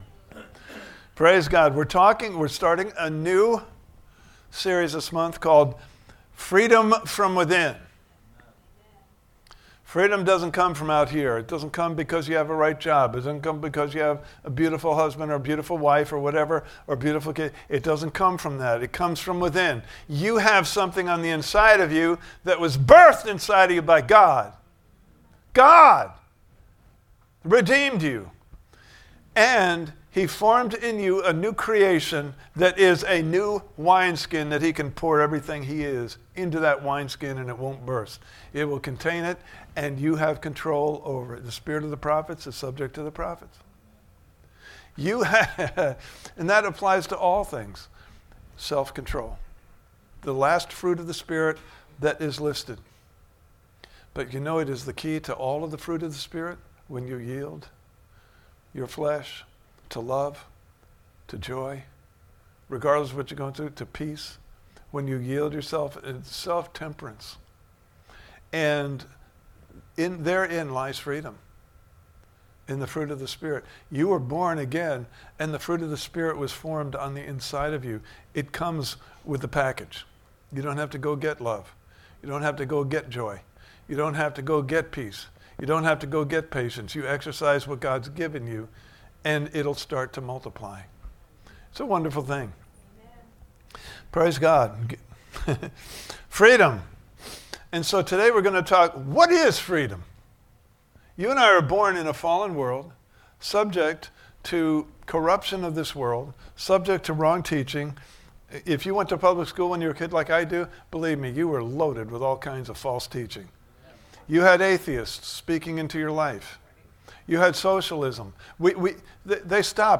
Freedom From Within Service Type: Sunday Morning Service « Part 5